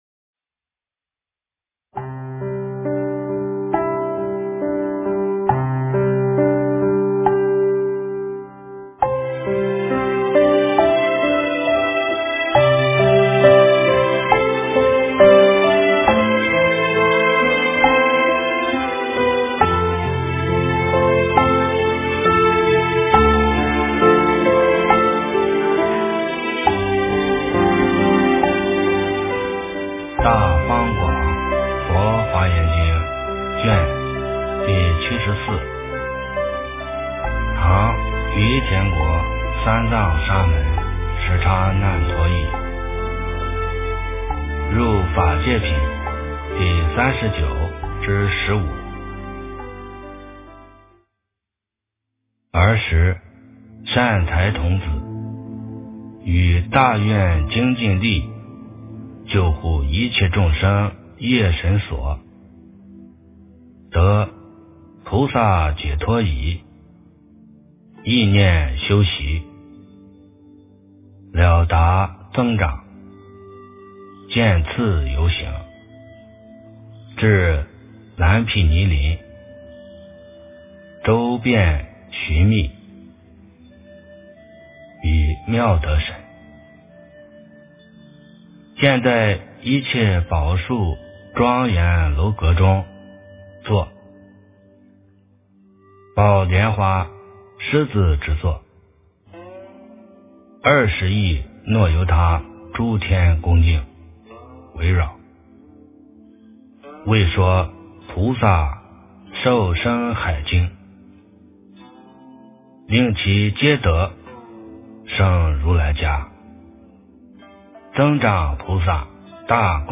诵经
佛音 诵经 佛教音乐 返回列表 上一篇： 《华严经》71卷 下一篇： 《华严经》75卷 相关文章 Om Sai Ram--The Buddhist Monks Om Sai Ram--The Buddhist Monks...